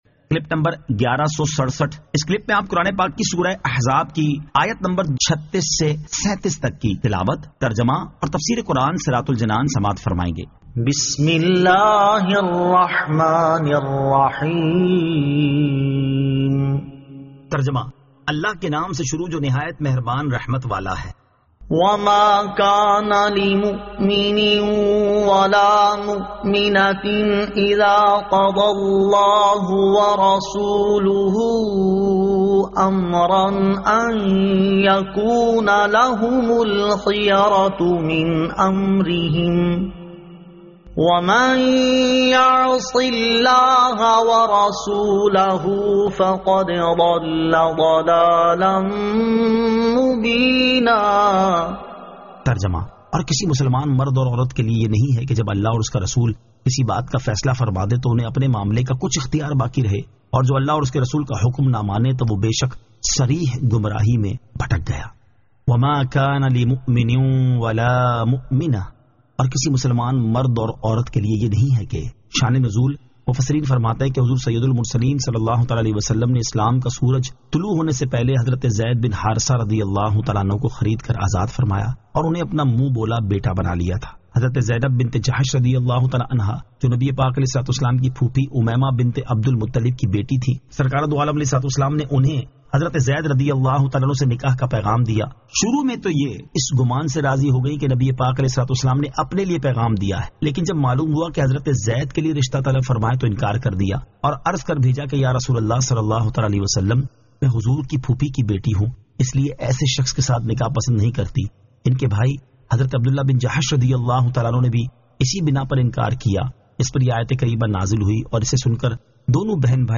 Surah Al-Ahzab 36 To 37 Tilawat , Tarjama , Tafseer